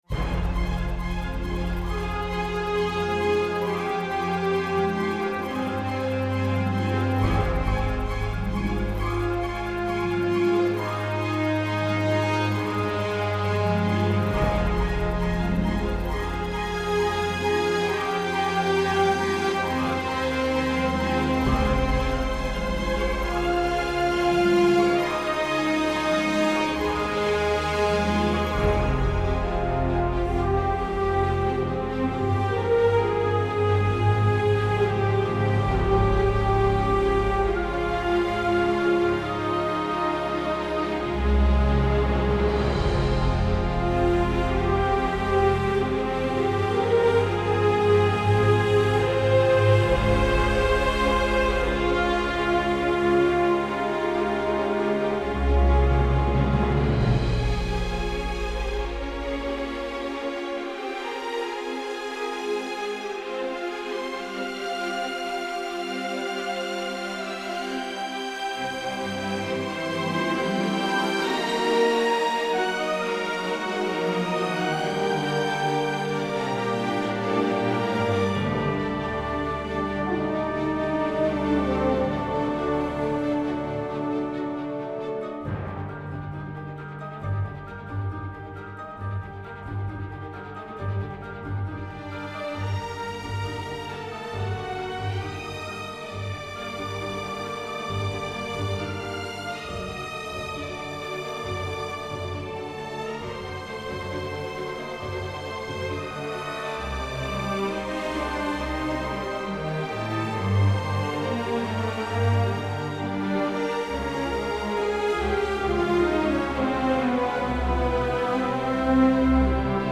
Нажмите для раскрытия... к примеру вот этот миди-мокап к какому фильму подошёл бы?
И кто из простых зрителей или даже того же режиссёра может сказать что это не живой оркестр?